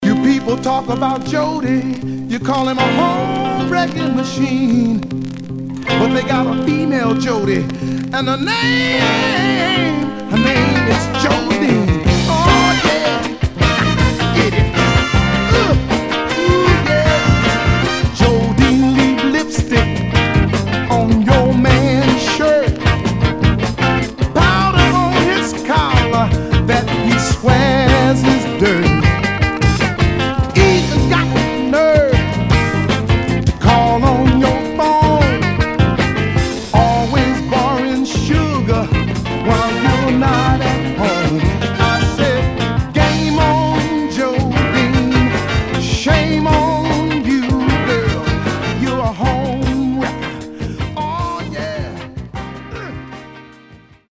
Mono
Funk